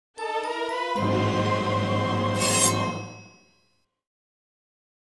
sword.mp3